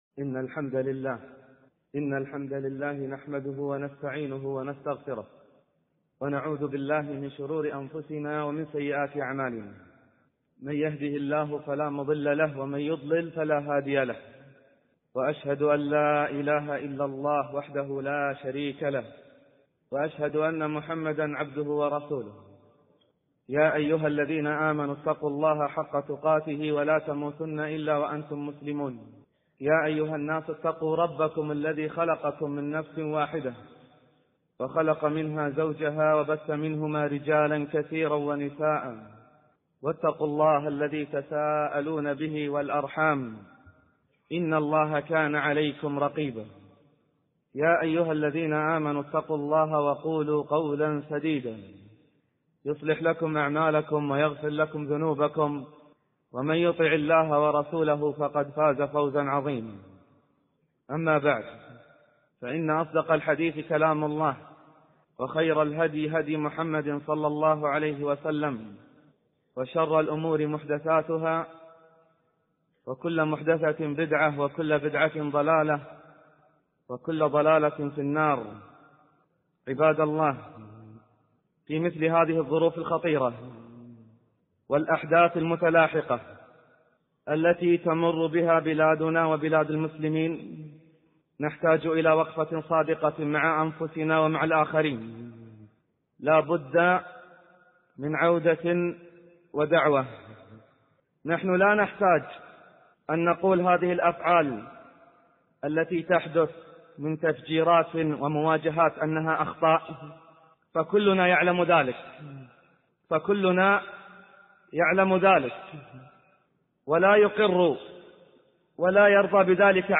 المقدمة والخطبة